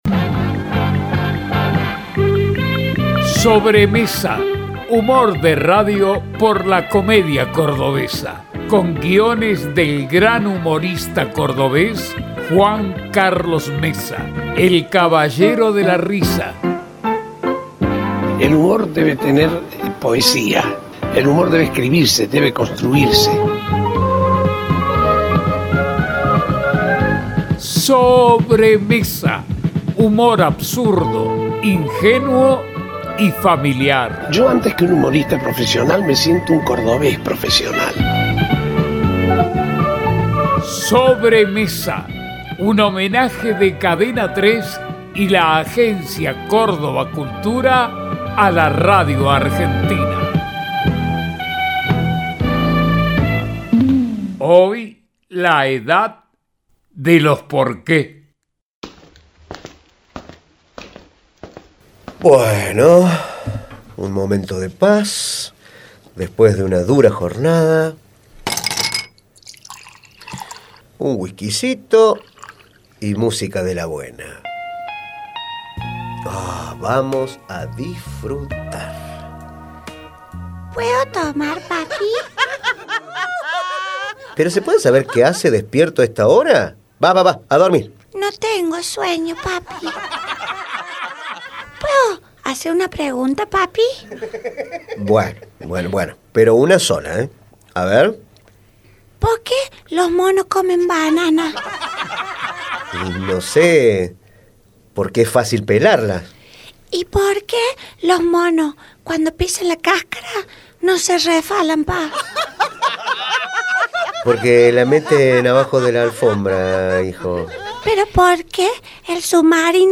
Uno de los 20 sketches basados en guiones del comediante y protagonizados por actores de la Comedia Cordobesa. Se emiten durante agosto en el programa Viva la Radio.